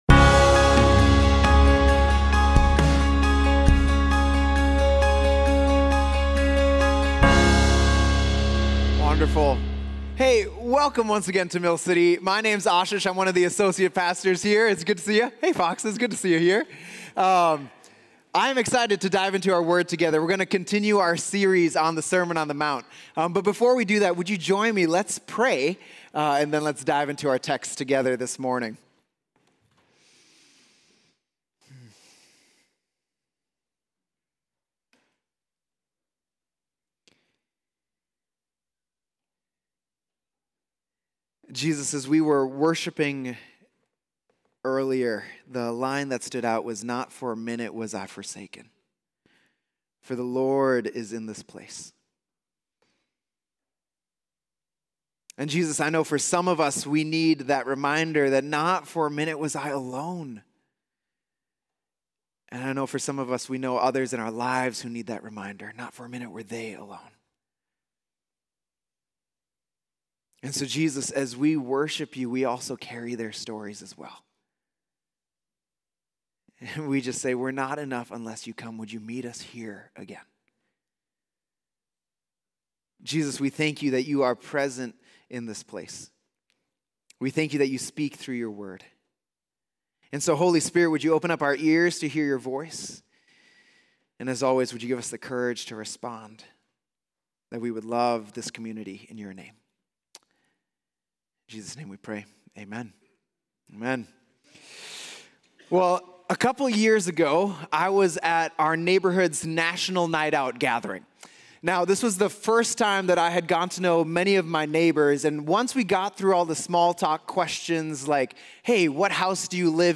Mill City Church Sermons We are the Salt and Light Oct 29 2024 | 00:32:11 1x 00:00 / 00:32:11 Subscribe Share RSS Feed Share Link Embed